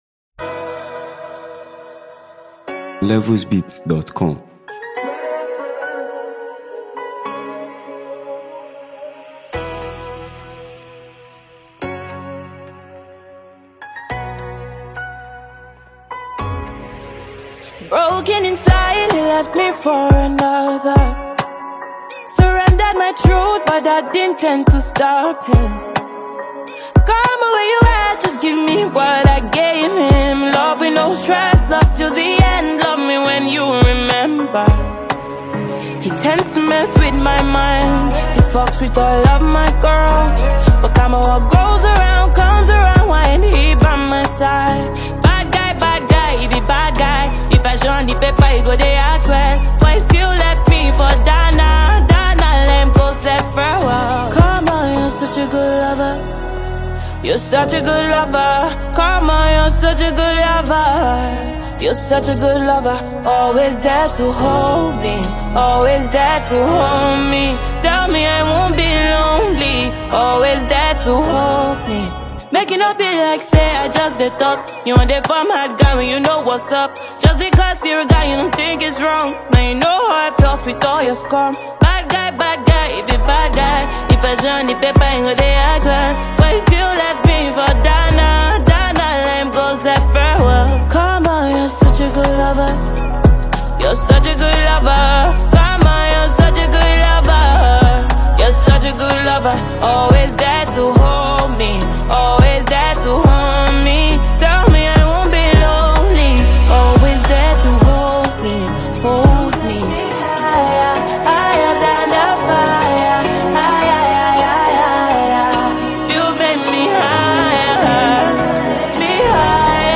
Nigeria Music 2025 2:48